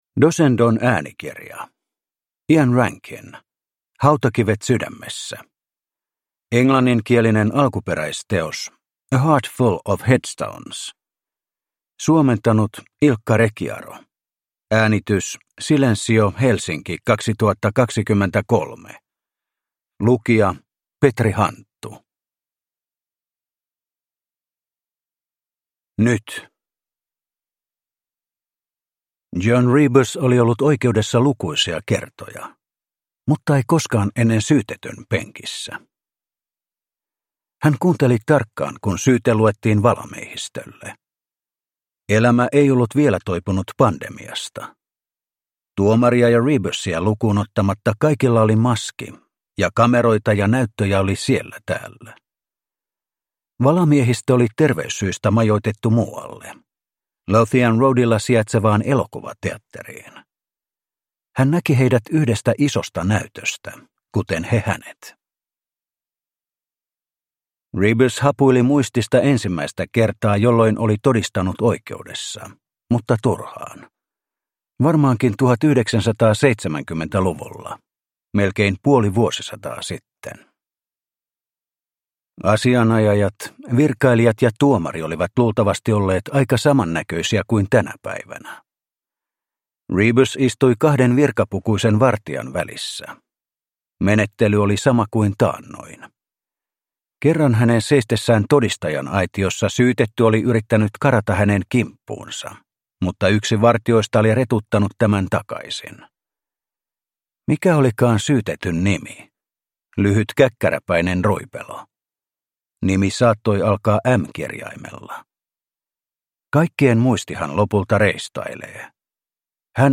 Hautakivet sydämessä – Ljudbok – Laddas ner